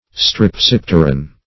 Search Result for " strepsipteran" : The Collaborative International Dictionary of English v.0.48: Strepsipter \Strep*sip"ter\, Strepsipteran \Strep*sip"ter*an\, n. (Zool.)